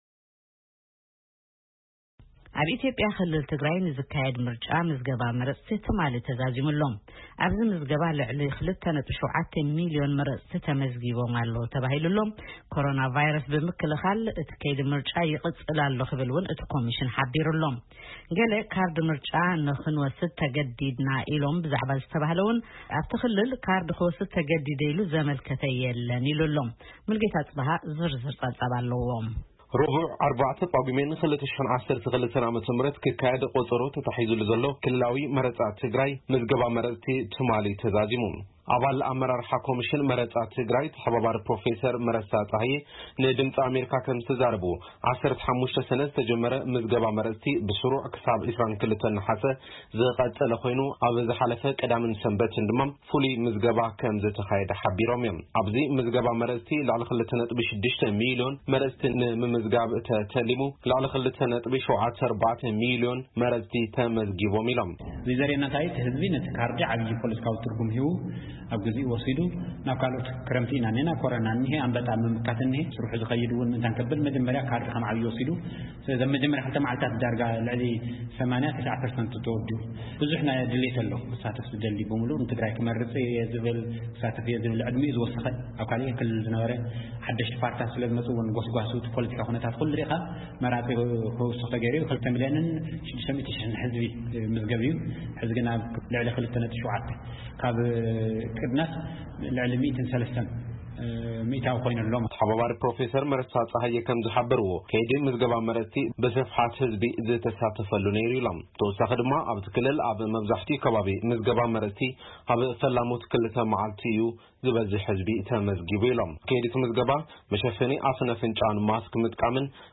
ንኣባል ኣመራርሓ እቲ ኮምሽን ተሓባባሪ ፕሮፌሰር መረሳ ፀሃየ ኣዘራሪብና ዝተዳለወ ጸብጻብ ኣብዚ ምስማዕ ይክኣል ::